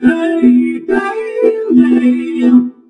VOXVOCODE3-R.wav